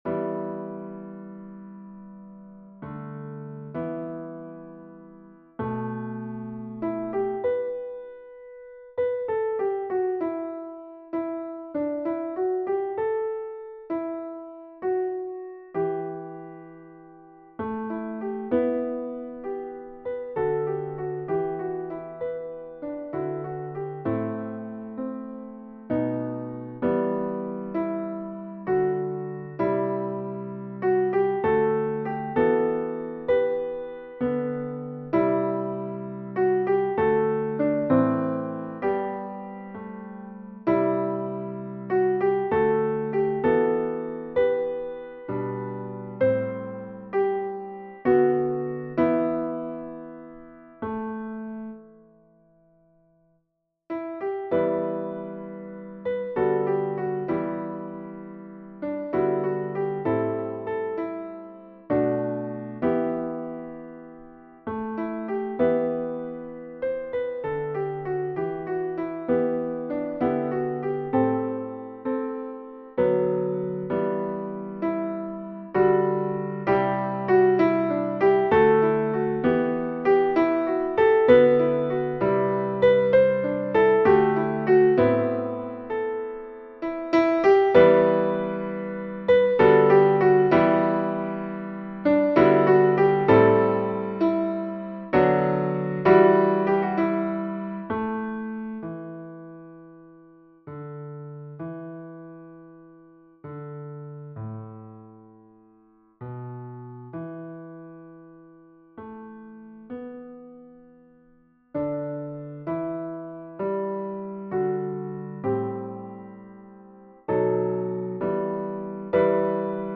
Versions "piano"